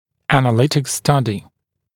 [ˌænə’lɪtɪk ‘stʌdɪ][ˌэнэ’литик ‘стади]аналитическое исследование